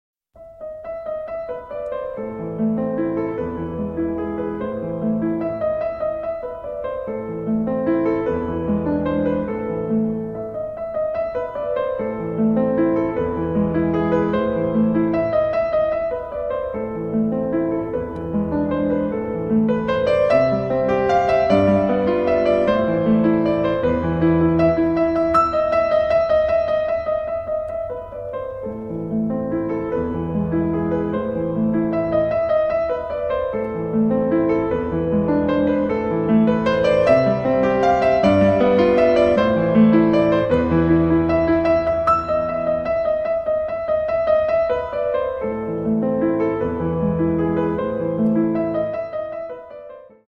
Classic